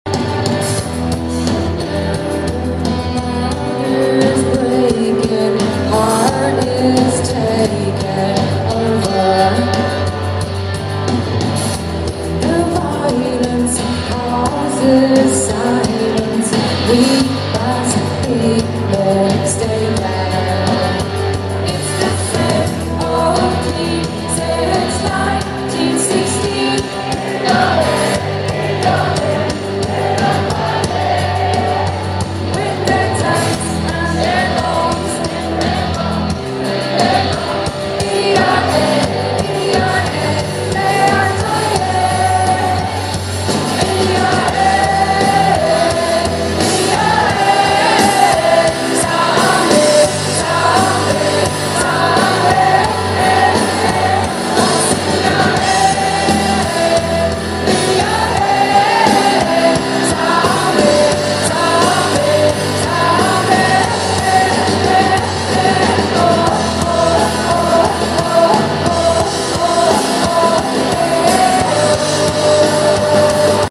Festival Netherlands 2016 July 9.